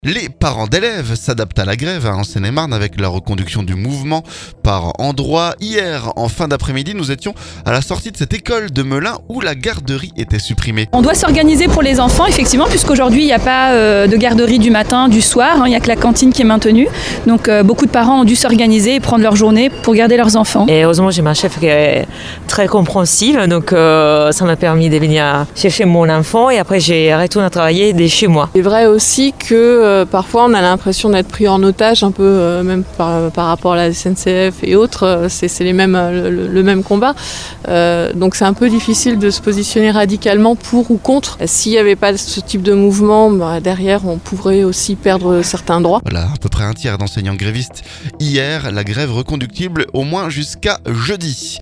Mardi en fin d'après-midi nous étions à la sortie de cette école de Melun, où la garderie était supprimée.